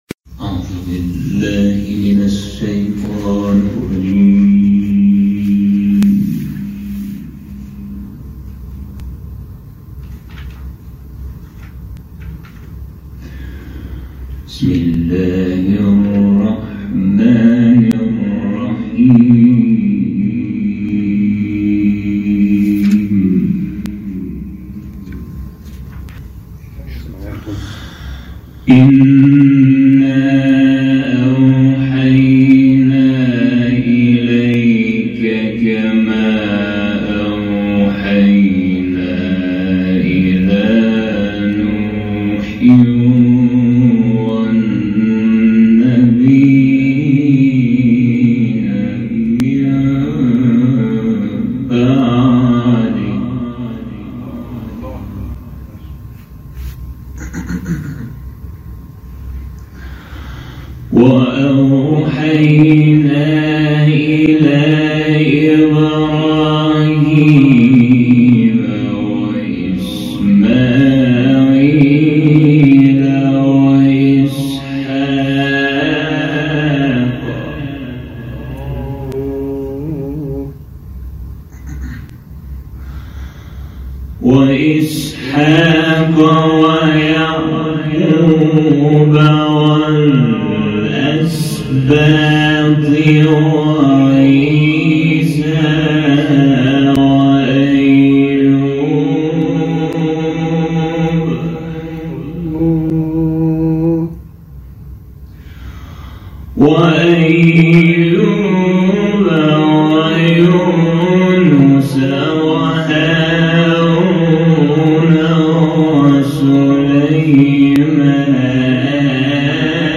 تلاوت کامل